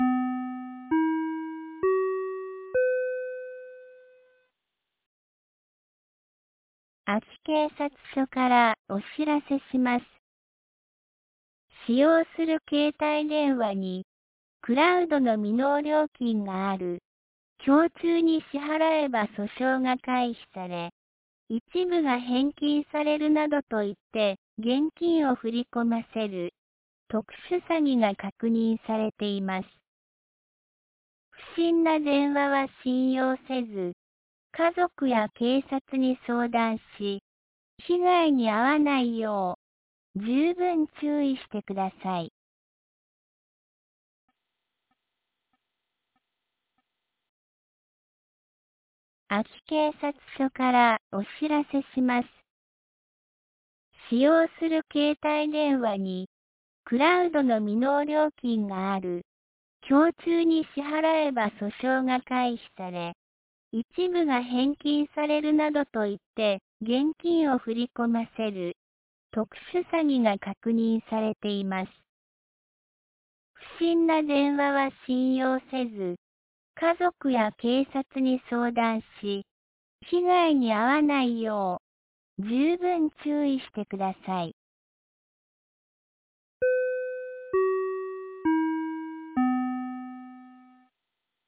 2024年12月06日 17時21分に、安芸市より全地区へ放送がありました。